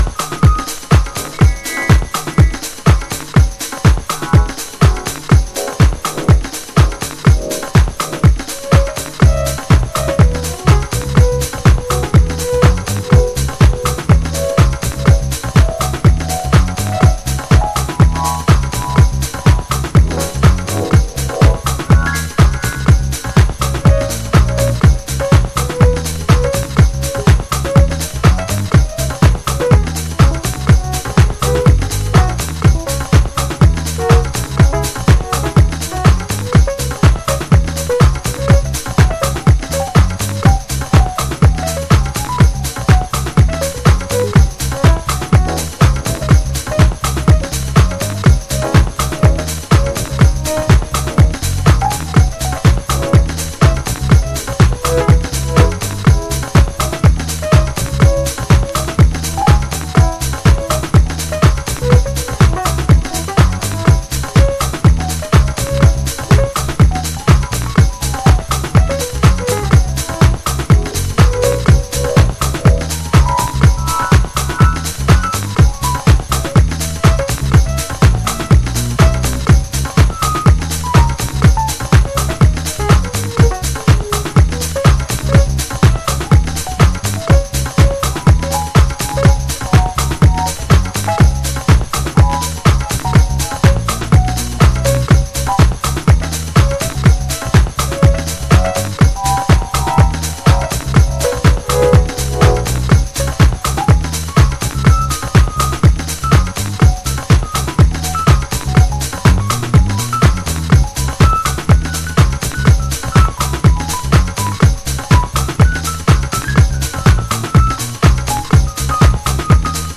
ザラっとした肌触りで荒々しくグルーヴするスバラシイリミックス。